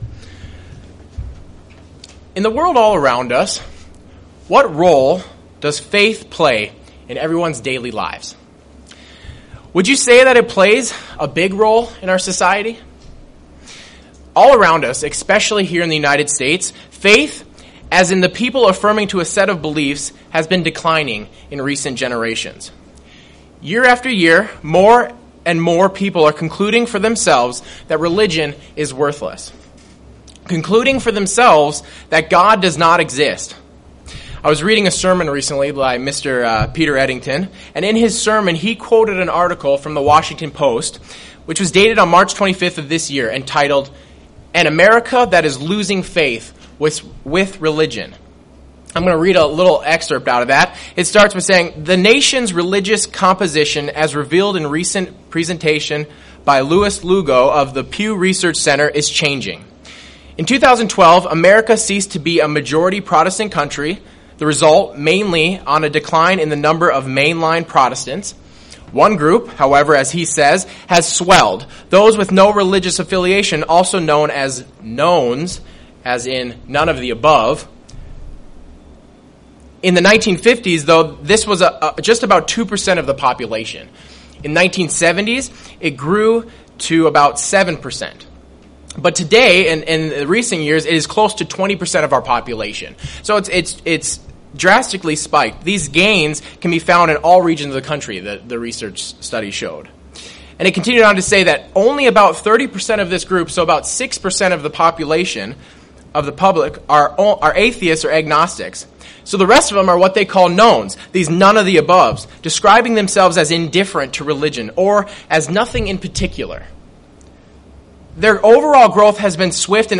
There are many different explanations into what faith is. In this sermon, the speaker looks into what the Bible has to say concerning what faith is.